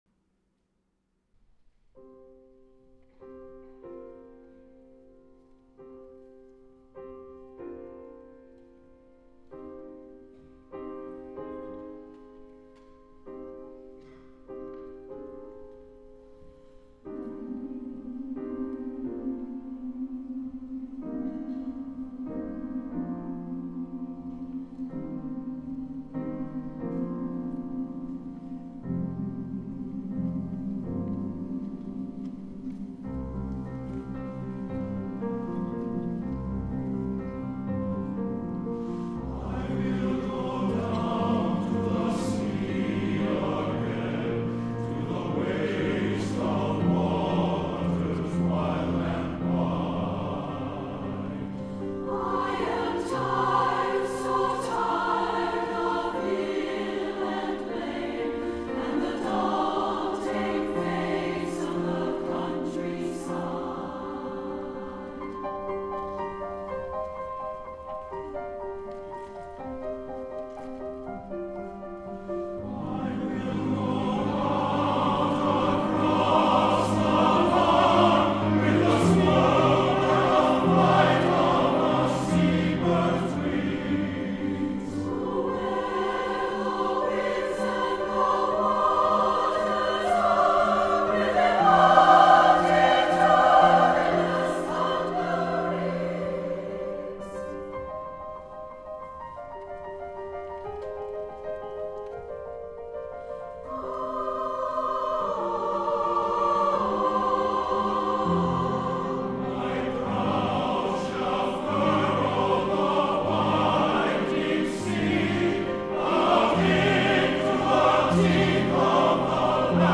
for SATB Chorus and Piano (2004)
This is sustained and powerful music, with the rolling waves often portrayed in the piano accompaniment.